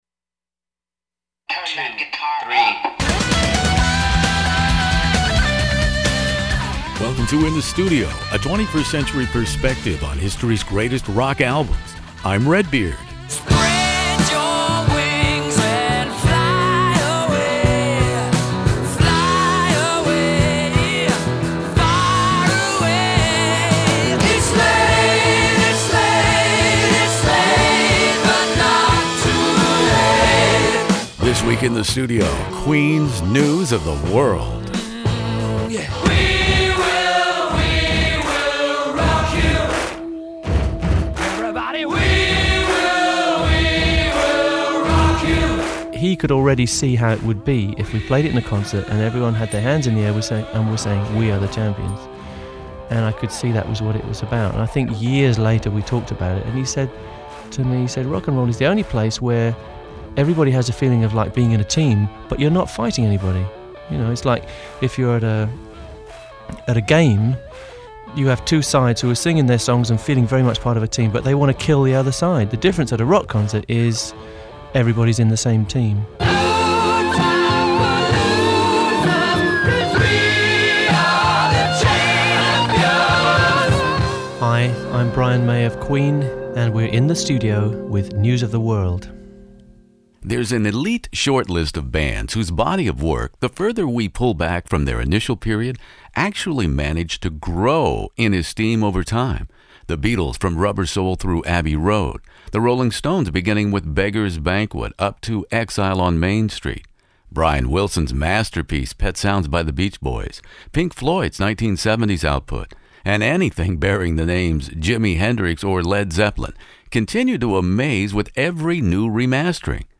With the October 1977 release of News of the World, London-based Queen moved into the upper echelons of international rock bands, with arena-filling (soon to be stadium-sized) anthems “We Will Rock You” and “We Are the Champions”. But my guest, Queen guitarist/composer Brian May, reminds us that News of the World also contains “Spread Your Wings”,”Get Down, Make Love”, and the under-appreciated mini-opera, “It’s Late” as well.